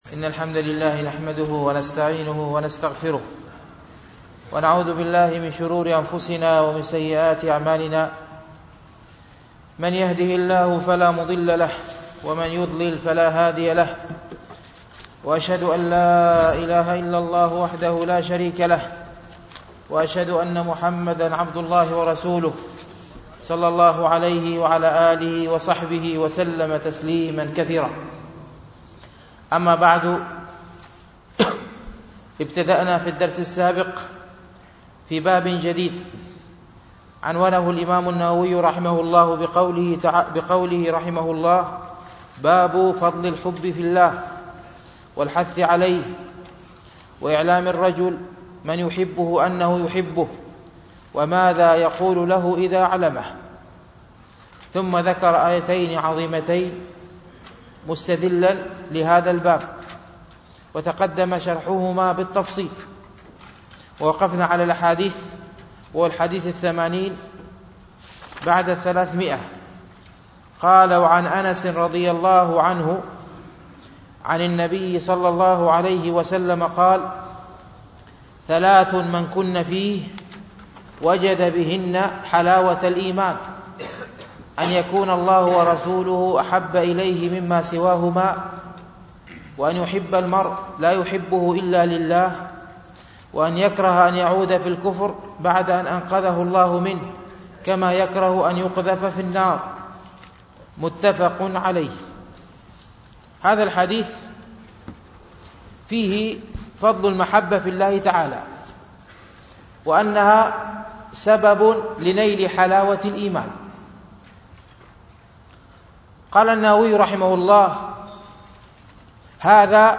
شرح رياض الصالحين ـ الدرس التاسع بعد المئة
MP3 Mono 22kHz 32Kbps (CBR)